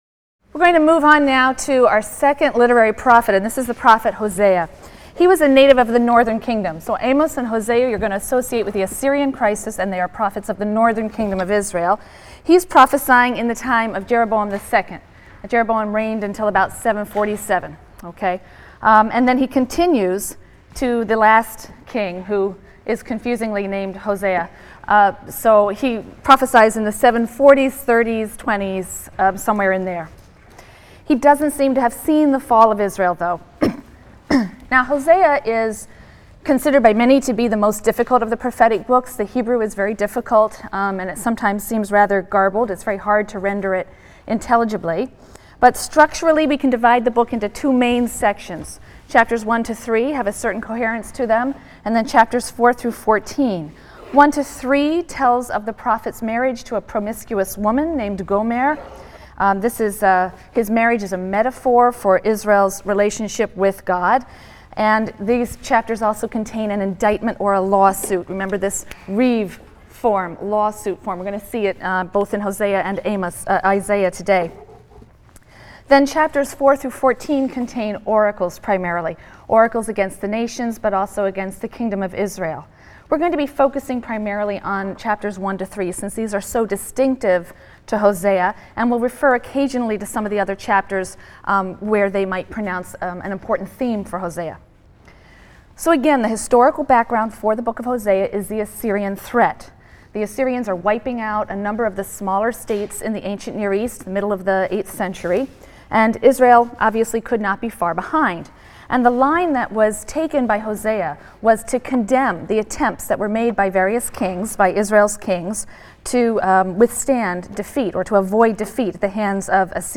RLST 145 - Lecture 17 - Literary Prophecy: Hosea and Isaiah | Open Yale Courses